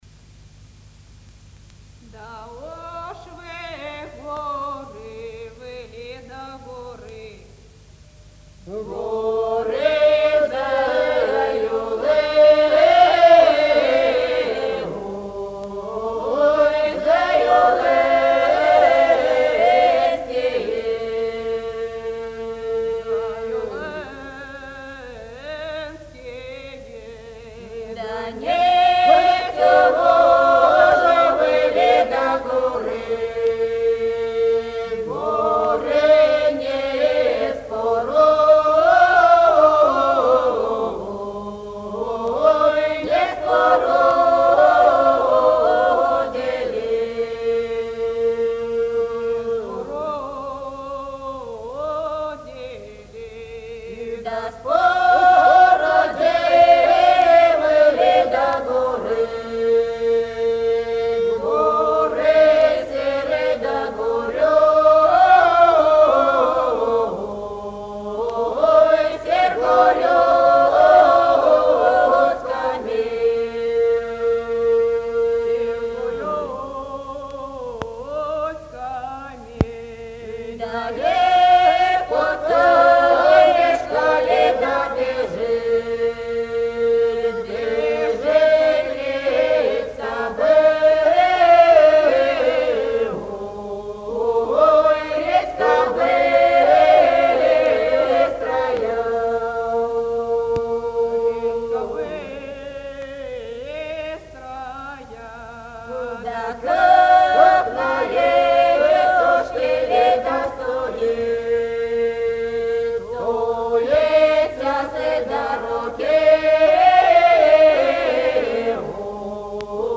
Для неё характерны протяжность, простор, широта  мелодии.
«Уж вы, горы Воробьёвские»  в исполнении фольклорного ансамбля